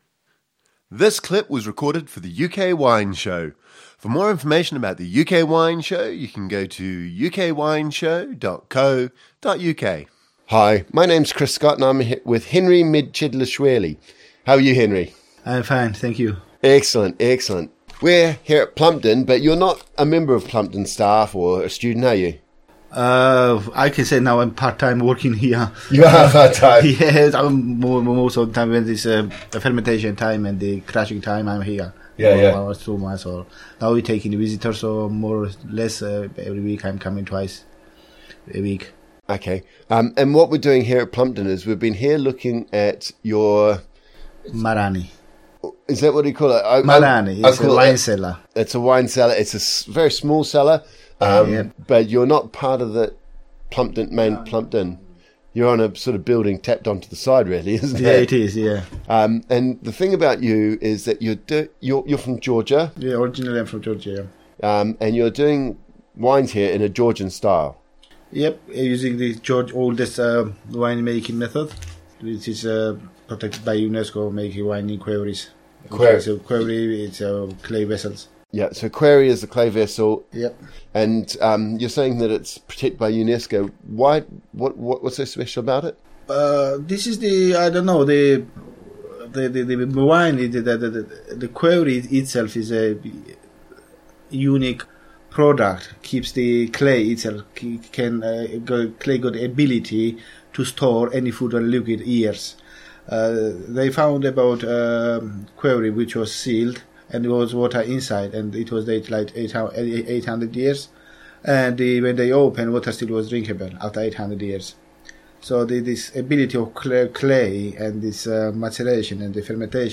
(pronounced querry). They are egg shaped, clay pots which come in different sizes and they were first used to make wine in 6000 BC, 8000 years ago (when the pots were discovered in 2017, grape seeds were found inside which meant scientists were able to carbon date the pots).